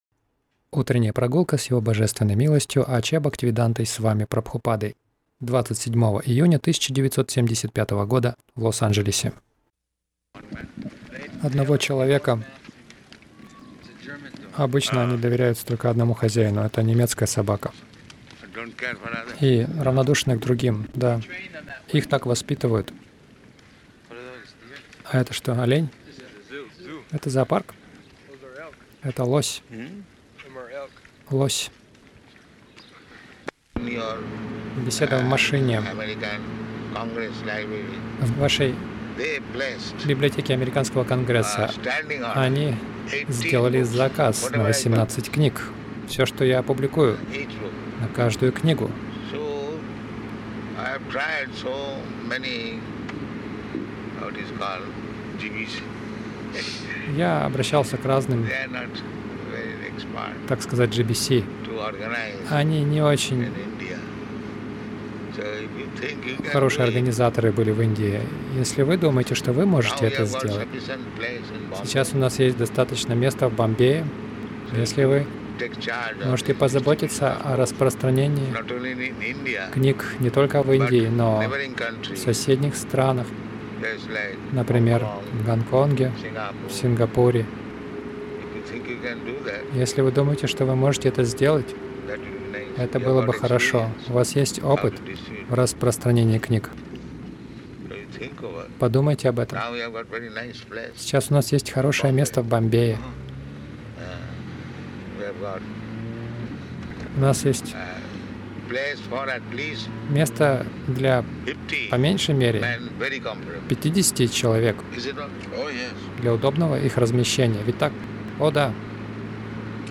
Милость Прабхупады Аудиолекции и книги 27.06.1975 Утренние Прогулки | Лос-Анджелес Утренние прогулки — Разумные люди будут покупать наши книги Загрузка...